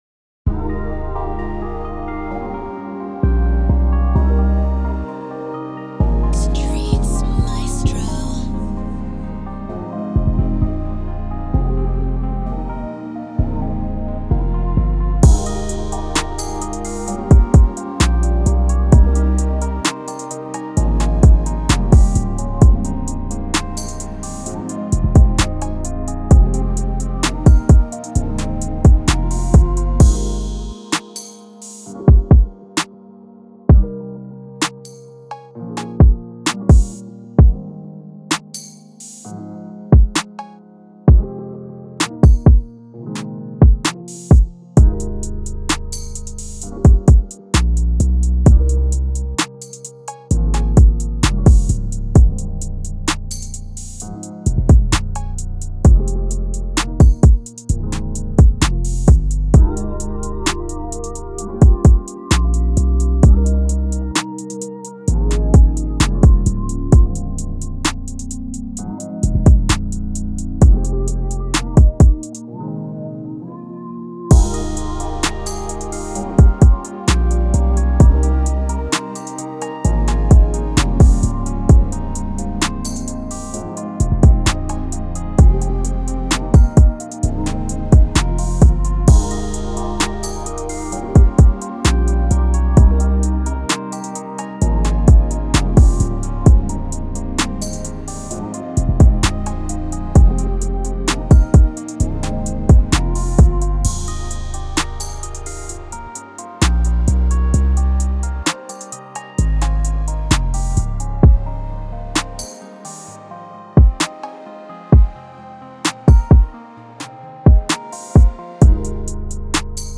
Moods: Laid back, smooth, intimate
BPM 147
Genre: R&B/Trapsoul
Tempo: 130